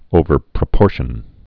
(ōvər-prə-pôrshən)